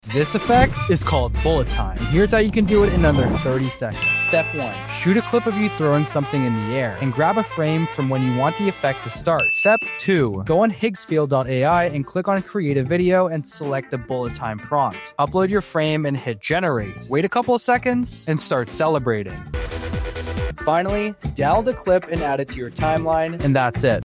This effect is called bullet sound effects free download
This effect is called bullet time